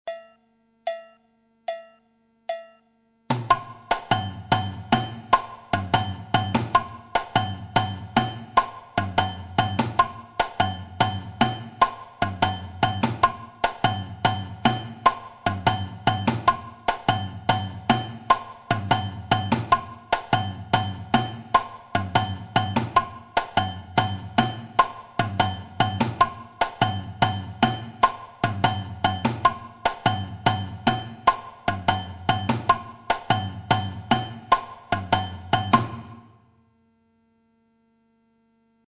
Plan rythmique du partido alto
Je réexpose la figure de la section rythmique de la samba page 1 et je rajoute un agogo en partido alto afin que vous puissiez voir les relations entre les instruments.Regardez bien ou se trouve les accents de l'agogo.section rythmique Partido alto 1 Téléchargez ou écoutez dans le player.